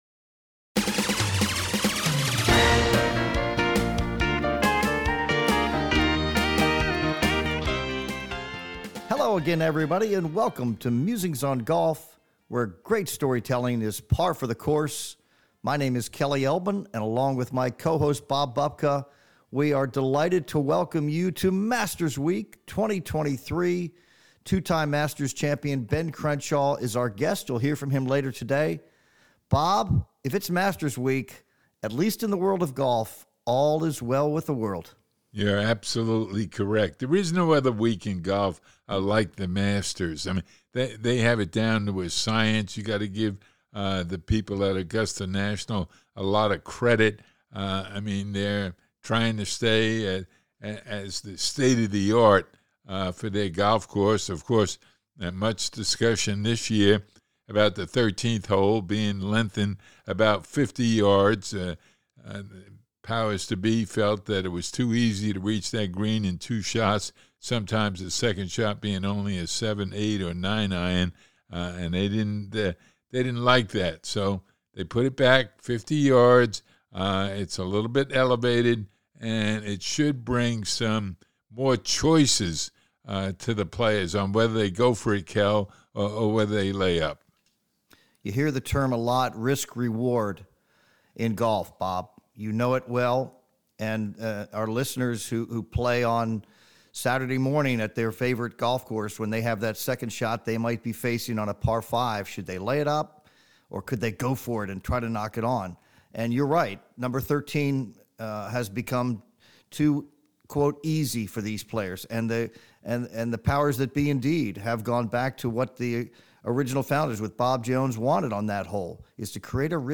Headliner Embed Embed code See more options Share Facebook X Subscribe It's Masters week 2023, and we are thrilled to be joined by two-time Masters Champion Ben Crenshaw. Hear Ben recall his wins in '84 and '95 and who he expects to be wearing the Green Jacket on Sunday. Also, you have to hear what Tiger, Rory and Scottie Scheffler have to say about their chances of winning.